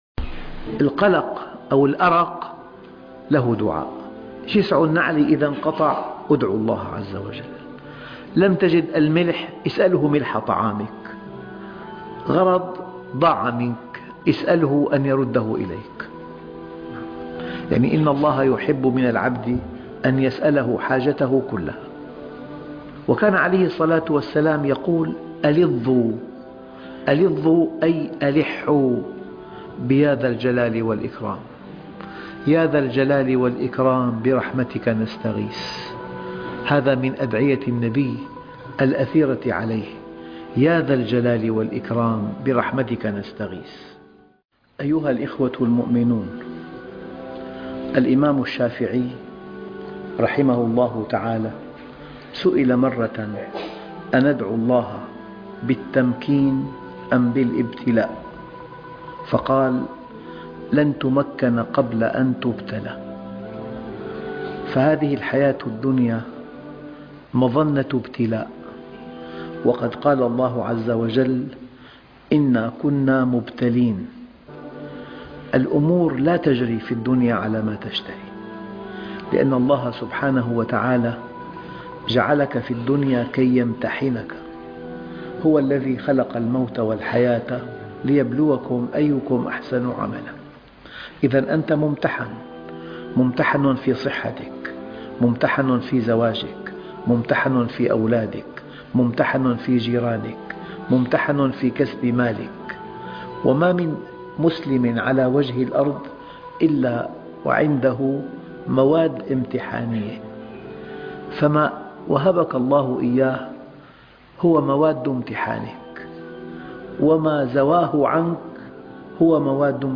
إذا ضاقت بك الدنيا وأثقلتك الهموم.. أغمض عينيك واسمع هذا الدرس - الشيخ محمد راتب النابلسي